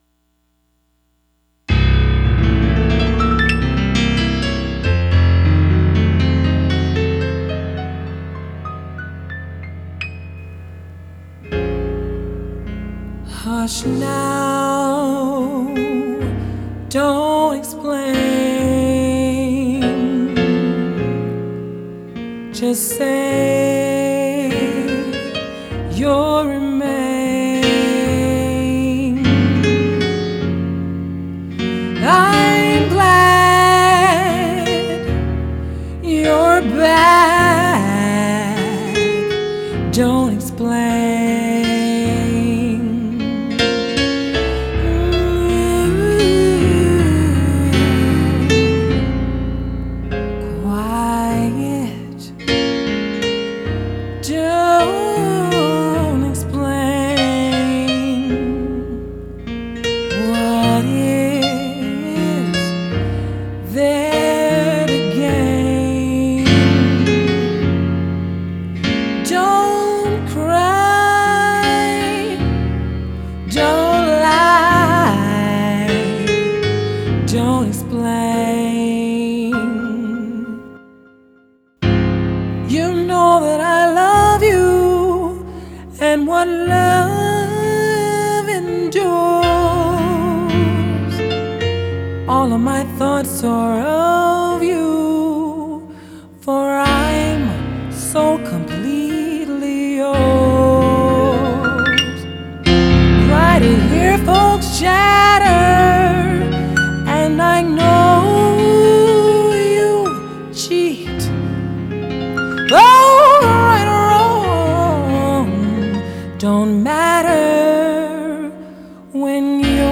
keyboardist